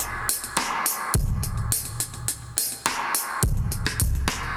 Index of /musicradar/dub-designer-samples/105bpm/Beats